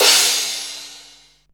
Crashes & Cymbals
CYMBAL_3002.WAV